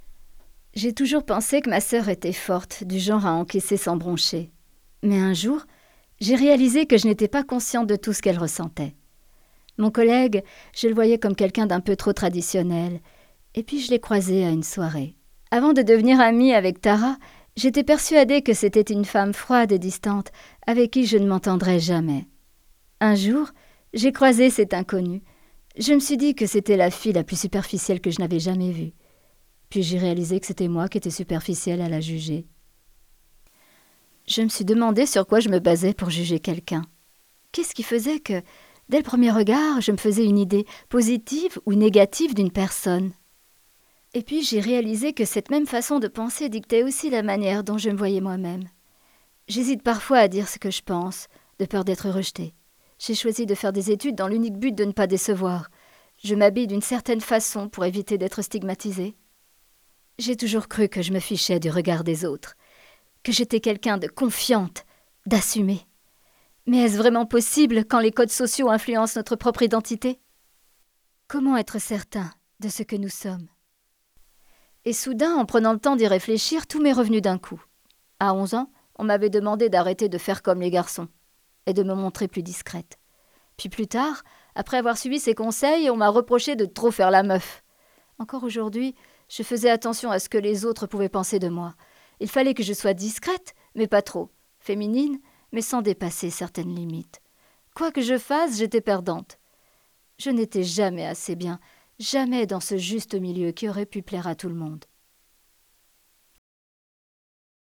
Voix Maman Laura podcast kidikoi